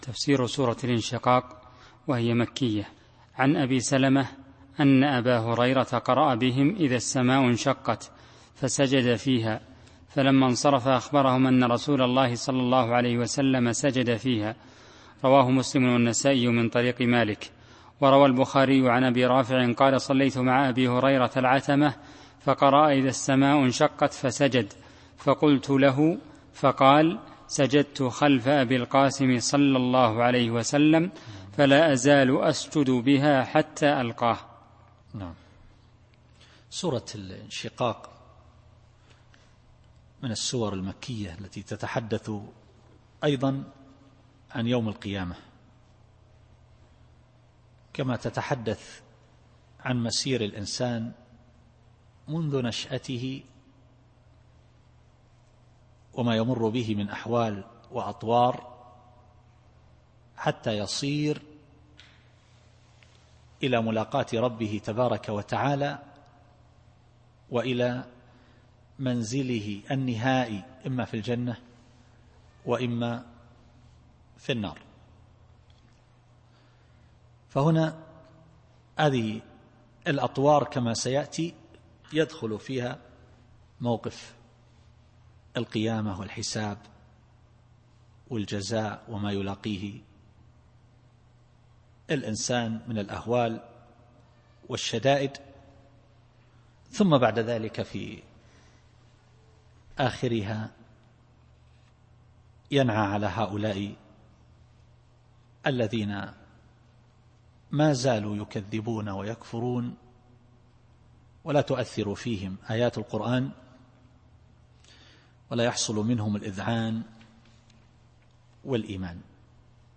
التفسير الصوتي [الانشقاق / 2]